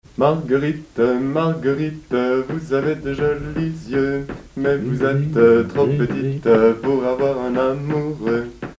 danse : mazurka
circonstance : bal, dancerie
Pièce musicale inédite